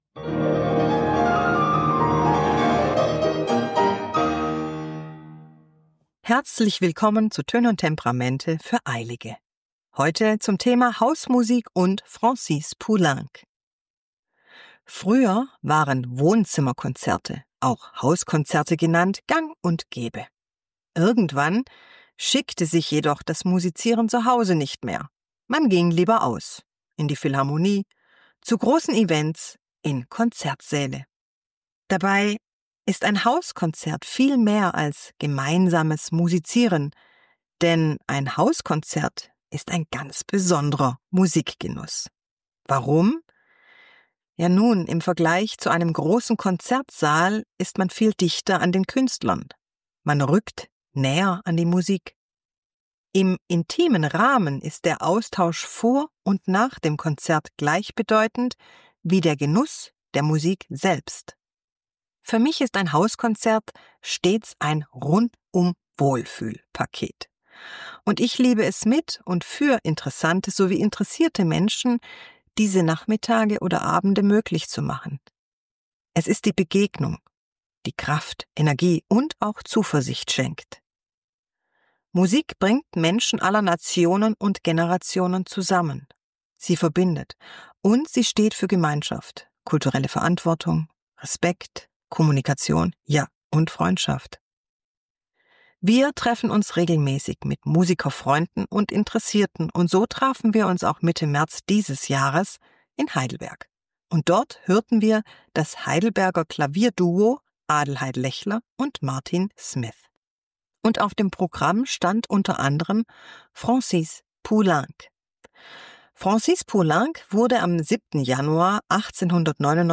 #34 "T&T für Eilige": Hauskonzert mit Francis Poulenc ~ Töne & Temperamente - das wohltherapierte Klavier Podcast
Die Klangfarben der beiden Klaviere werden für meine Ohren nicht vermischt, sondern dialogisch gegenübergestellt.